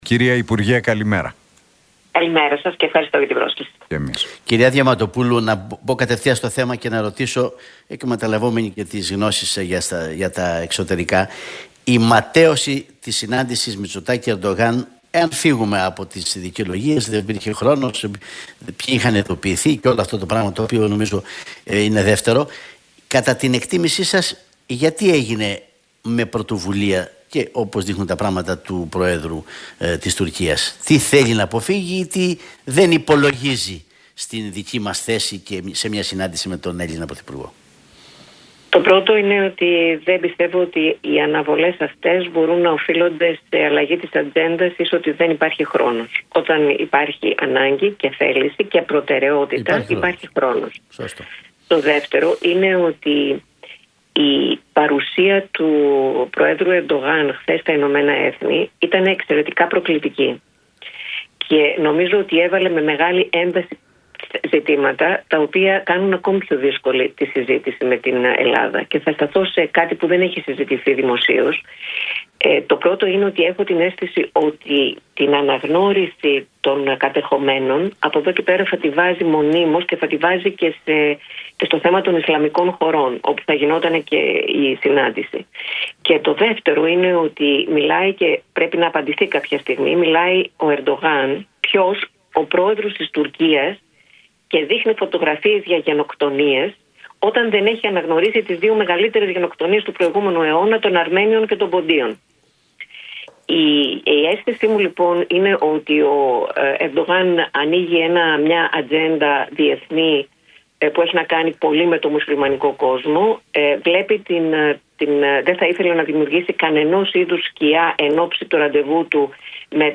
Η Άννα Διαμαντοπούλου, υπεύθυνη Πολιτικού Σχεδιασμού του ΠΑΣΟΚ, μίλησε στον Realfm 97,8 για τις ελληνοτουρκικές σχέσεις, υπογραμμίζοντας ότι ο Ερντογάν